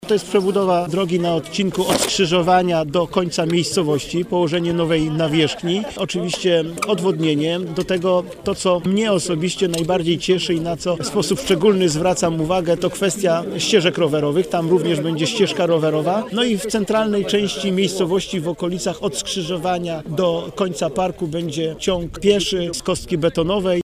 A chodzi o przebudowę blisko 1,7 kilometra drogi. Mówi starosta niżański Robert Bednarz: